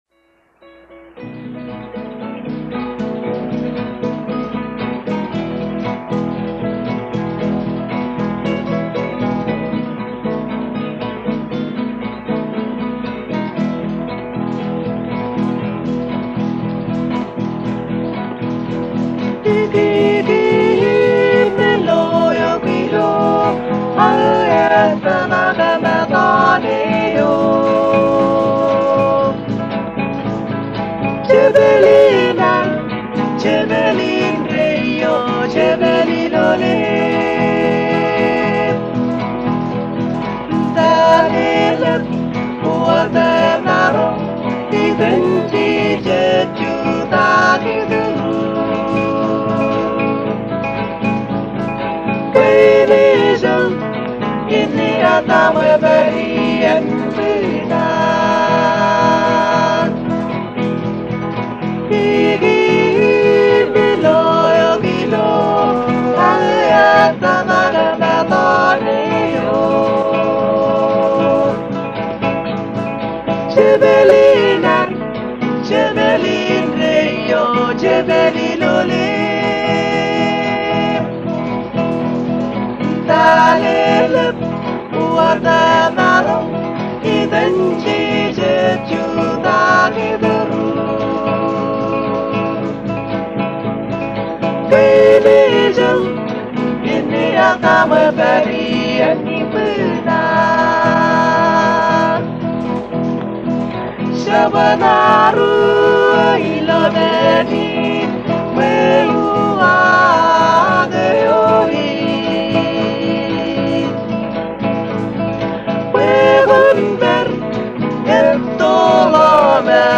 originally recorded between 1975 and 1985.